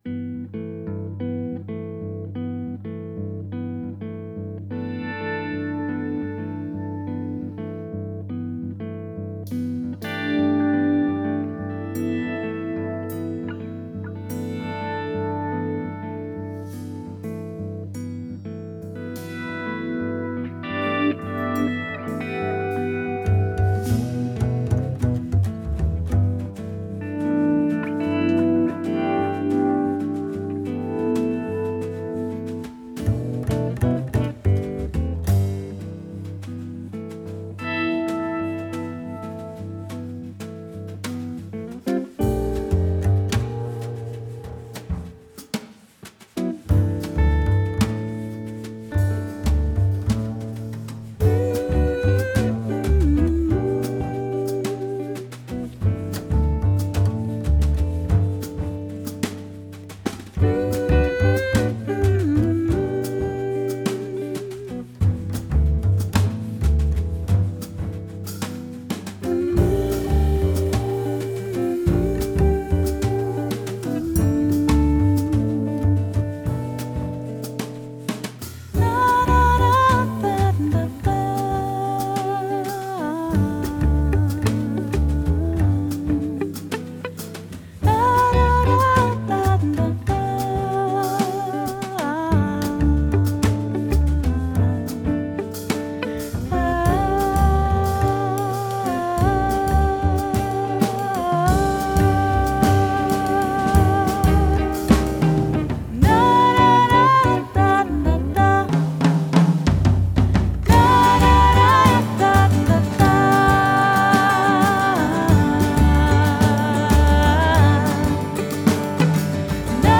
Genre: Jazz/Soul/Pop Vocals